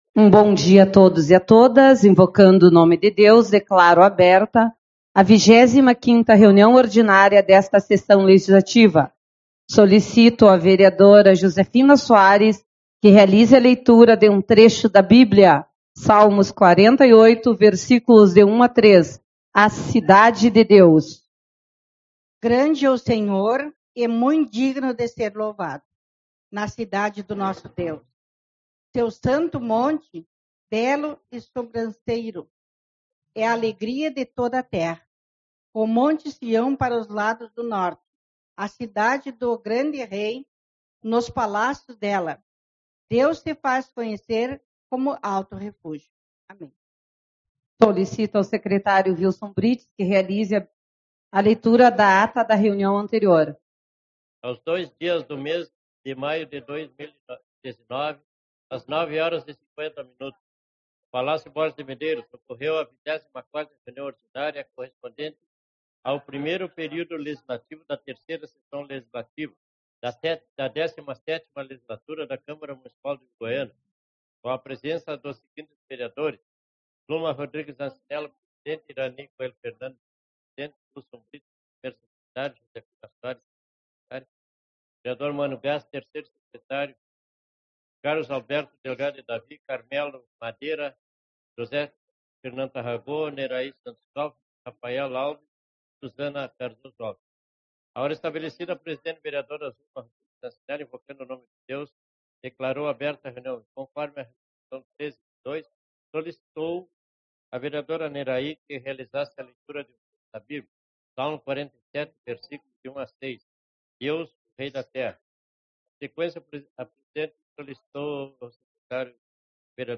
07/05 - Reunião Ordinária